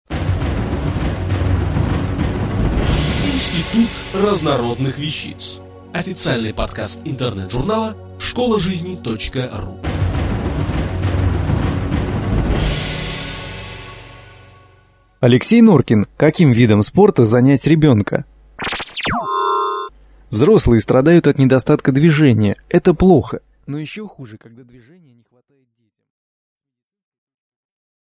Аудиокнига Каким видом спорта занять ребенка?